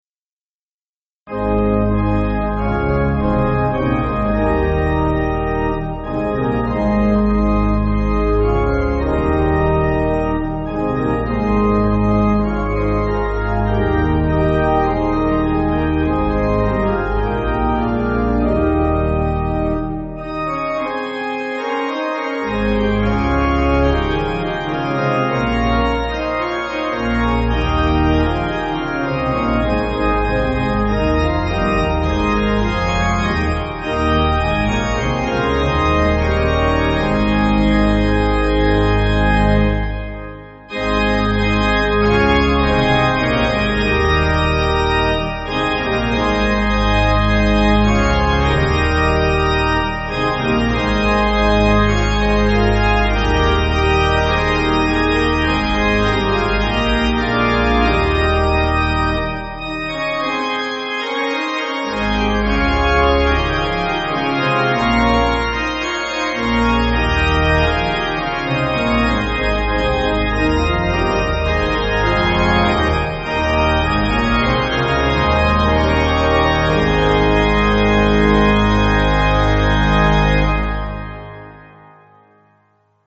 Organ
(CM)   2/Ab